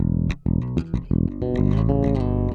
Loops de baixo 42 sons